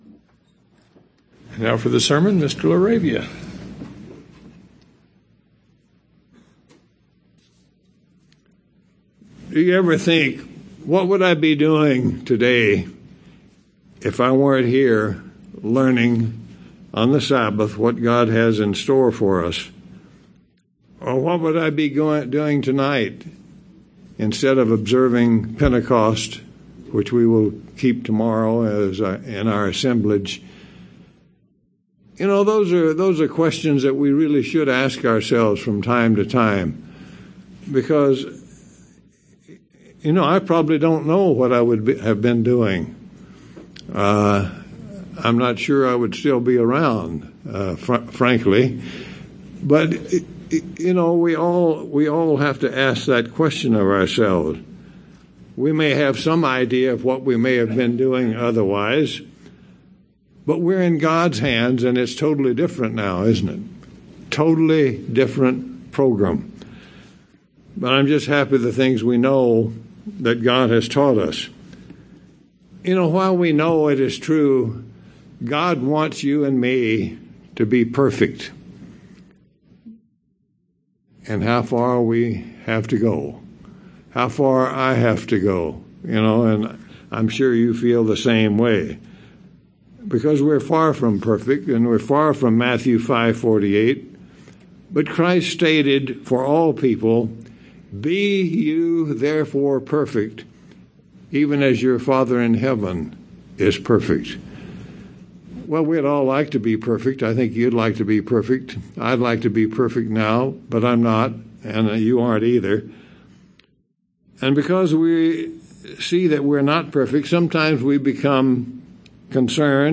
Given in Tucson, AZ El Paso, TX
UCG Sermon Studying the bible?